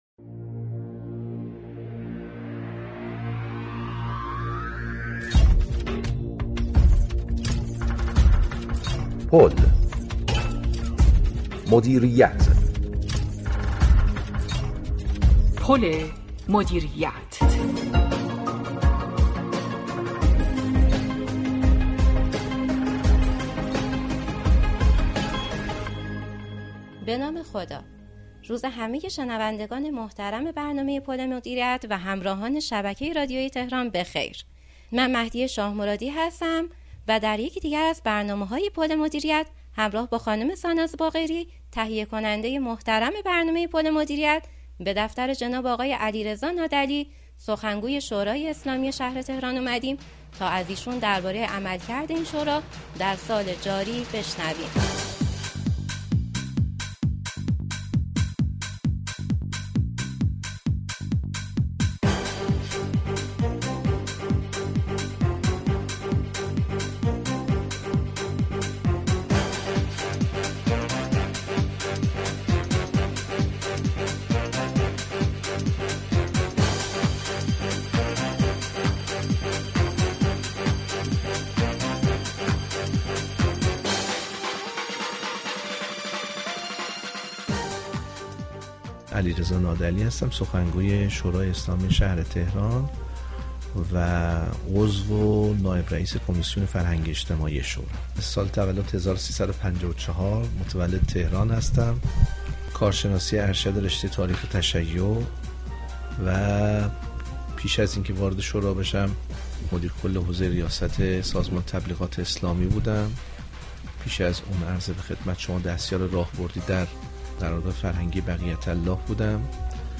به گزارش پایگاه اطلاع رسانی رادیو تهران؛ علیرضا نادعلی شهرستانكی عضو و سخنگوی شورای شهر تهران و نایب رئیس كمیسیون فرهنگی- اجتماعی این شورا در گفت و گو با برنامه پل مدیریت به پاره‌ای از پرسش‌ها در خصوص عملكرد این شورا در سال جاری پاسخ داد.